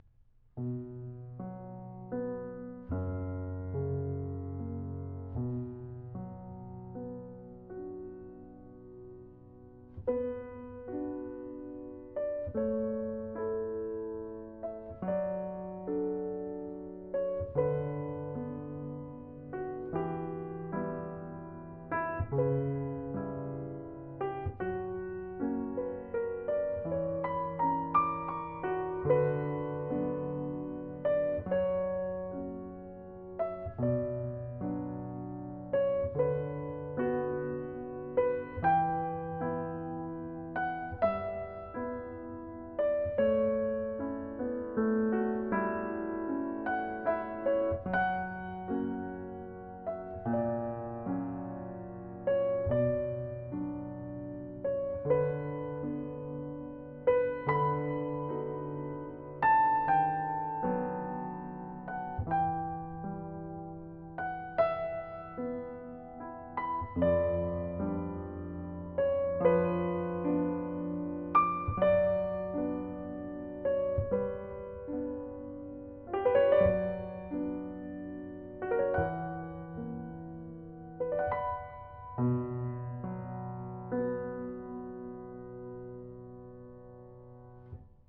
Piano accompaniment
Musical Period Romantic
Tempo 25
Rhythm Adagio 3/4 and 6/8
Meter 3/4